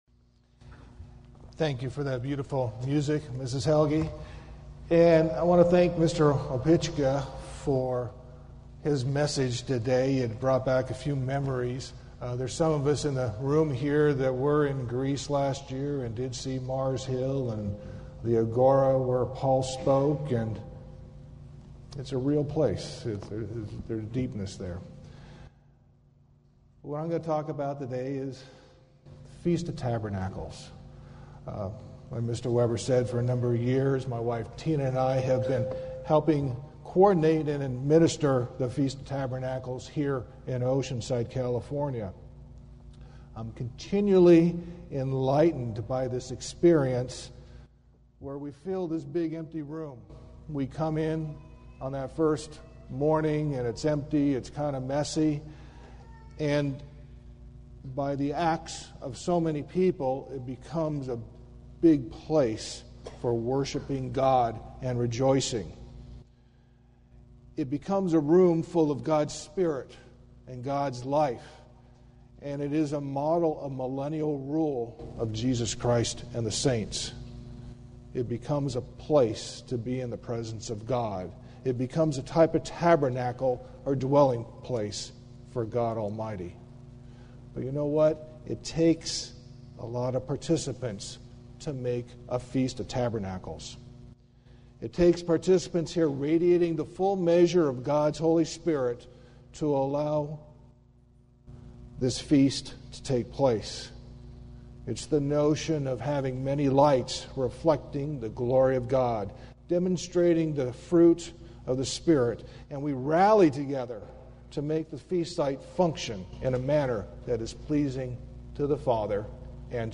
This sermon was given at the Oceanside, California 2018 Feast site.